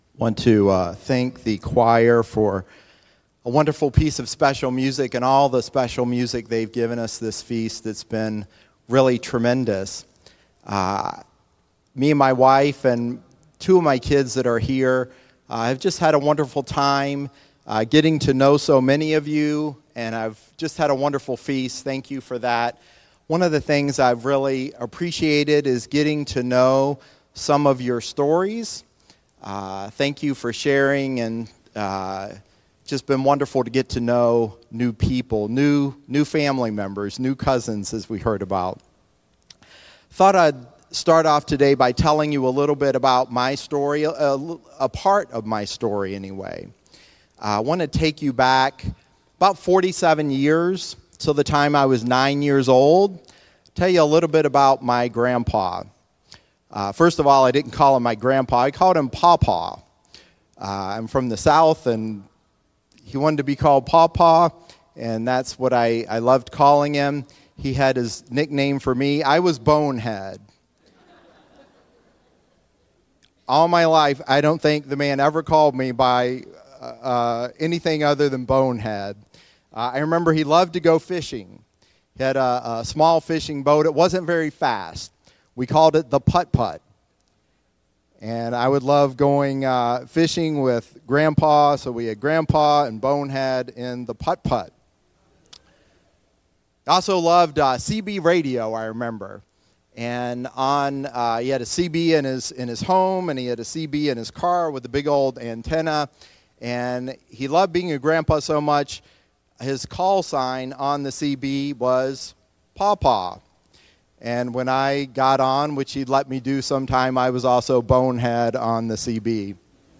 Given in Lake Geneva, Wisconsin